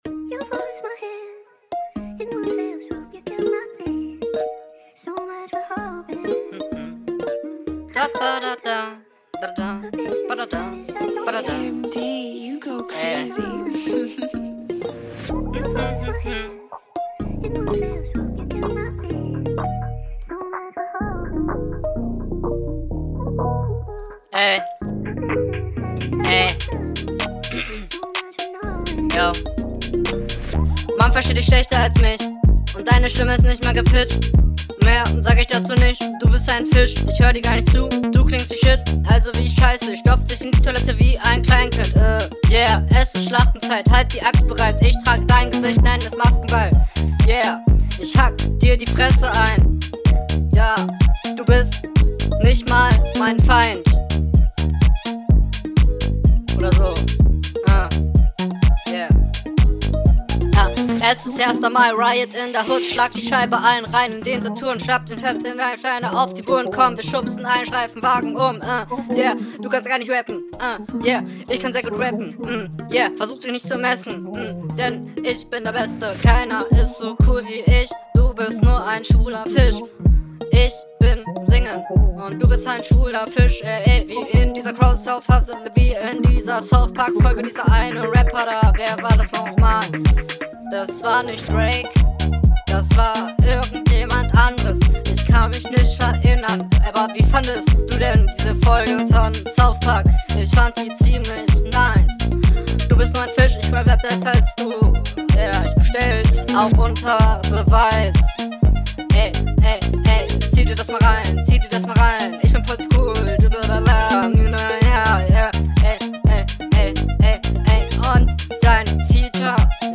Intro ähnlich unnötig lang. Der Flow wäre variantenreicher hat aber sehr viele random Pausen und …